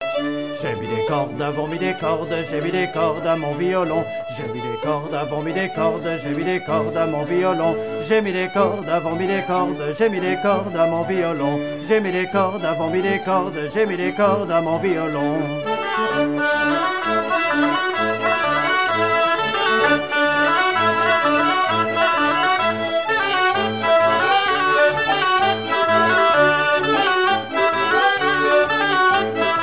Suite de branles - J'ai mis des cordes
Couplets à danser
danse : branle
Pièce musicale éditée